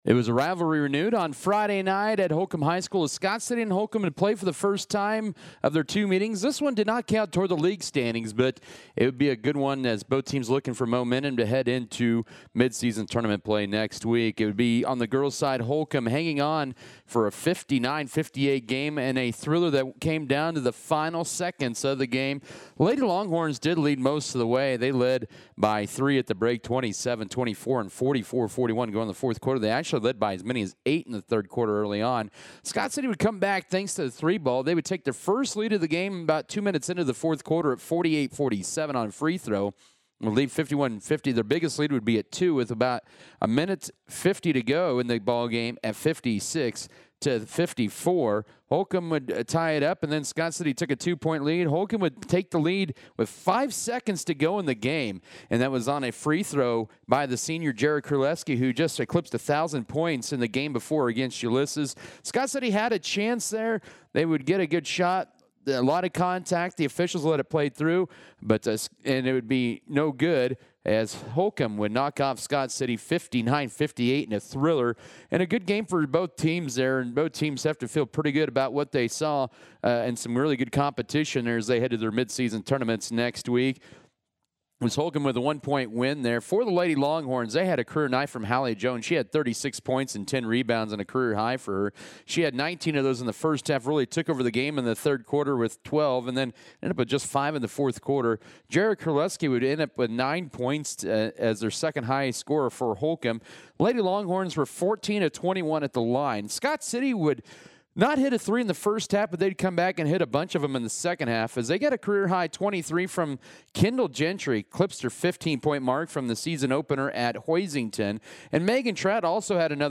Audio Game Recap